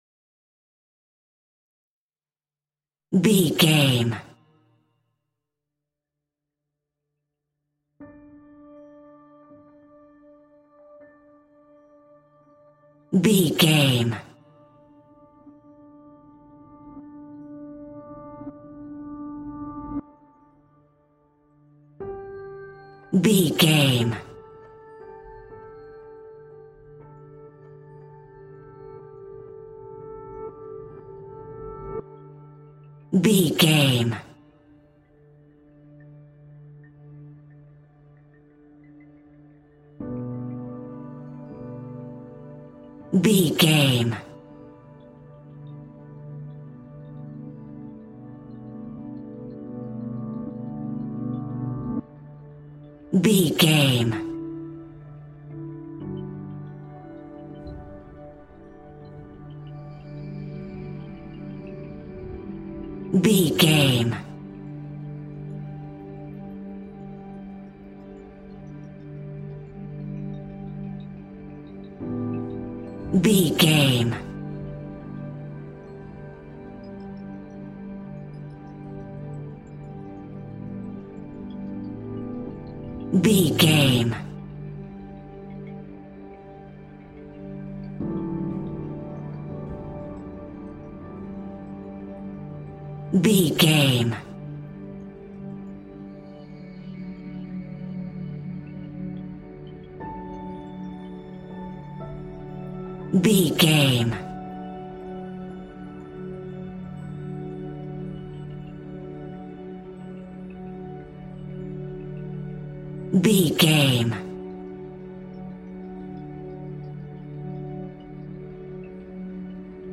Ionian/Major
D♭
chilled
laid back
Lounge
sparse
new age
chilled electronica
ambient
atmospheric
morphing